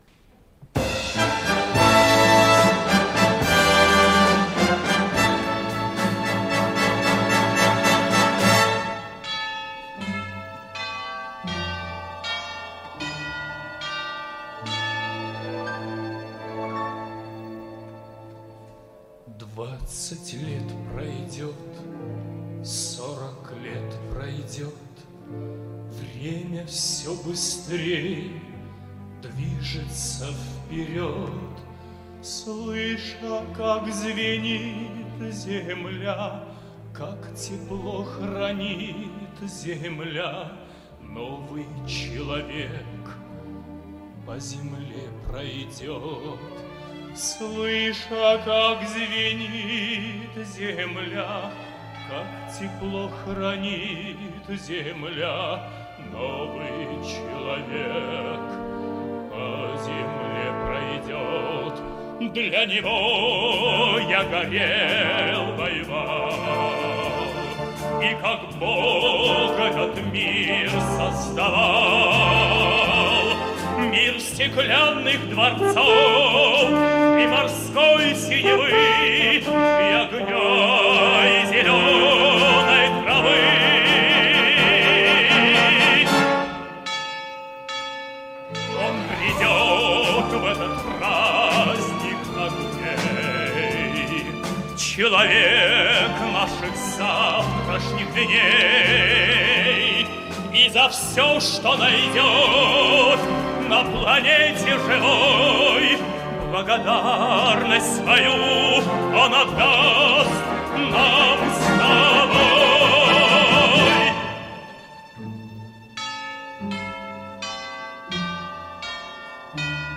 Концертное исполнение